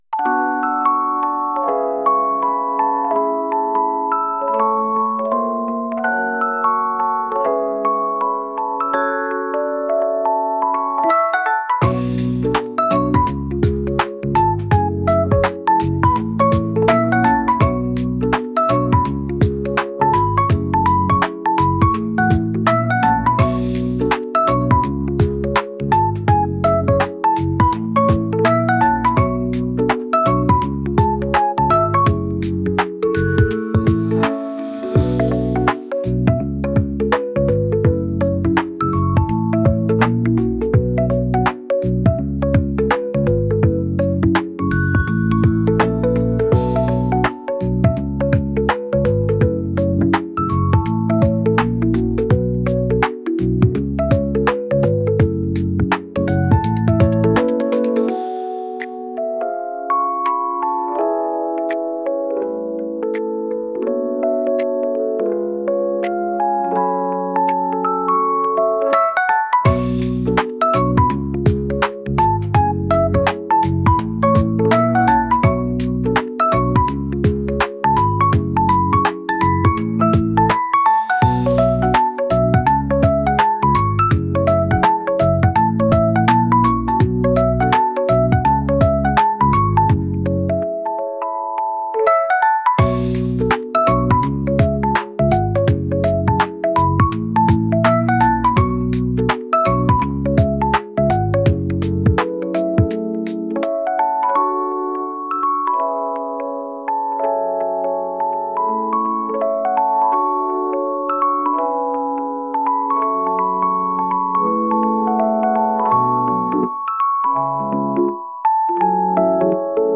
ただ何も考えずにぼんやりと窓の外を見ているような音楽です。